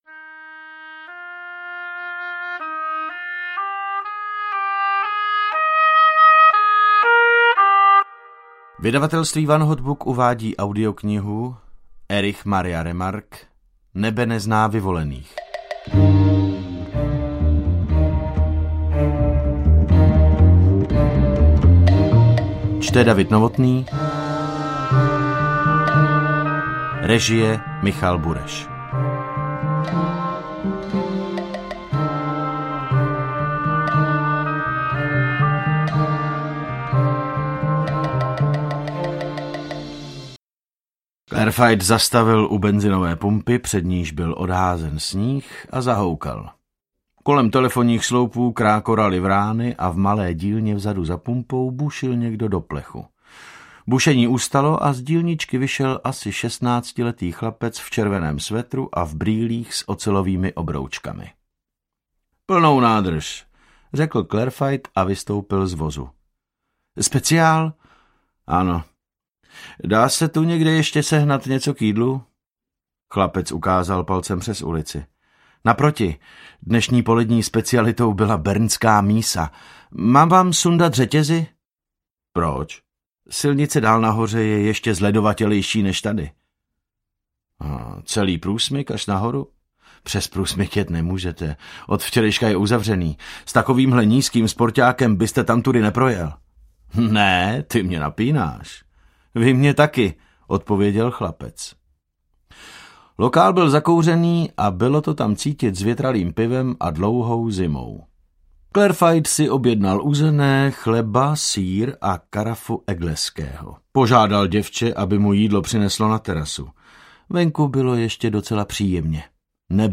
Nebe nezná vyvolených audiokniha
Ukázka z knihy